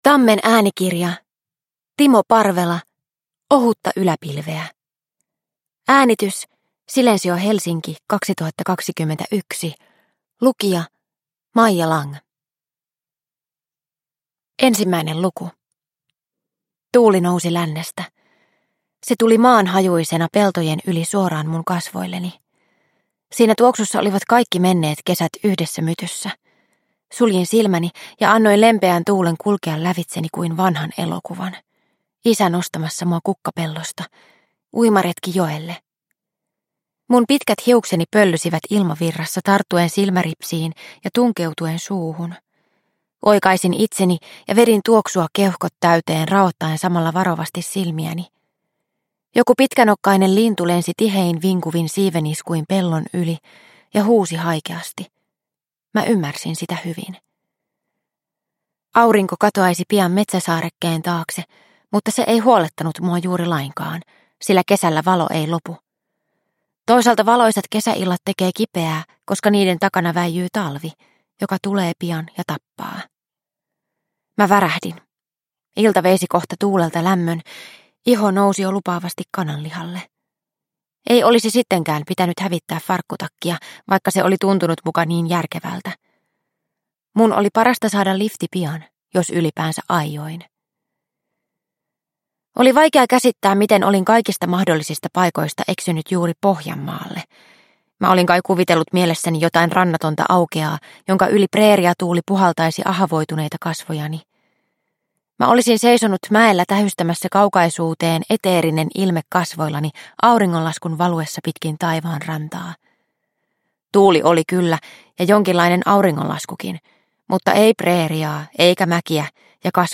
Ohutta yläpilveä – Ljudbok – Laddas ner